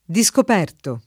vai all'elenco alfabetico delle voci ingrandisci il carattere 100% rimpicciolisci il carattere stampa invia tramite posta elettronica codividi su Facebook discoperto [ di S kop $ rto ] part. pass. di discoprire e agg.